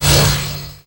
Magic_AuraHit02.wav